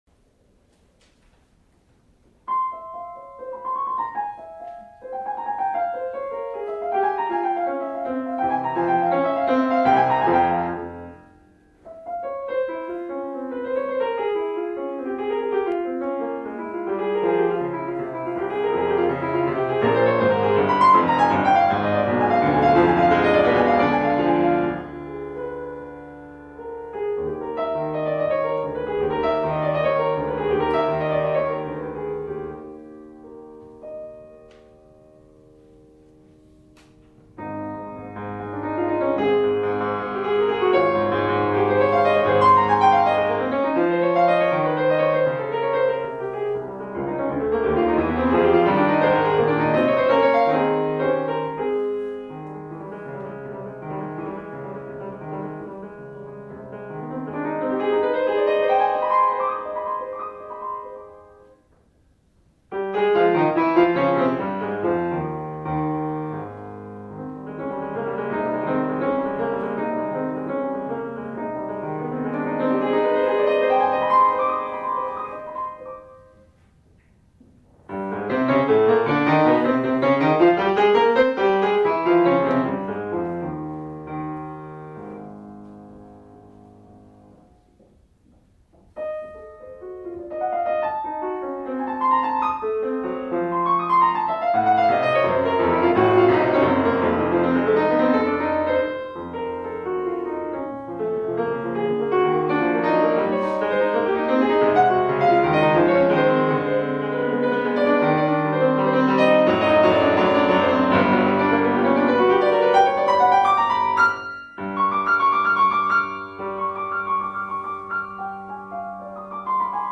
Second concert of the Beethoven-Clementi series at the Royal Academy of Music
piano
Concert Room
Allegro agitato [mp3]
This dramatic sonata points to the future, through its use of dynamic extremes, motivically unified movements, and novel pianistic virtuosity.